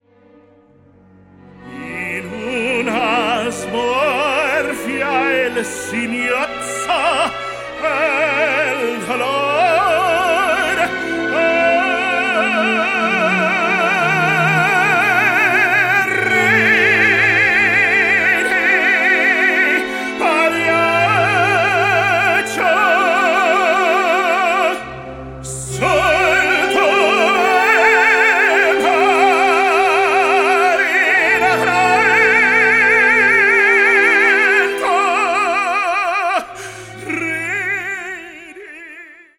Opera & Classical Crossover